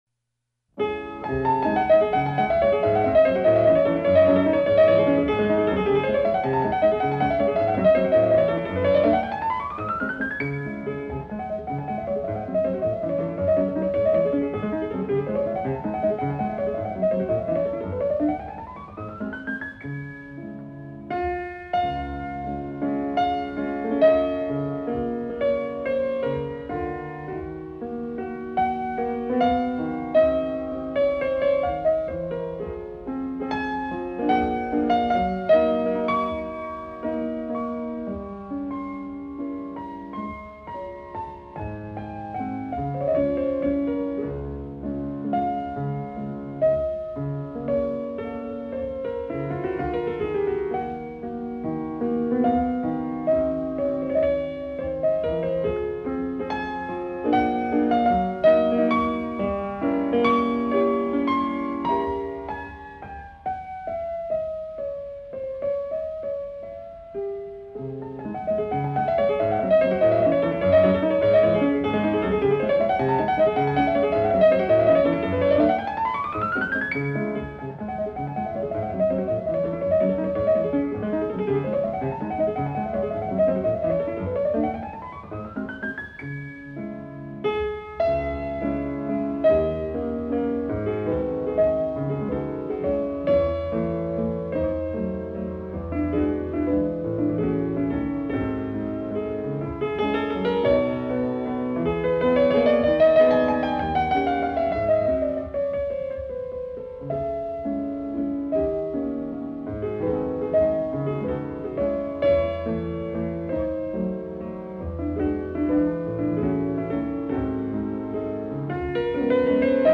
LAmrCrRGyhF_Waltz-in-c-minor.mp3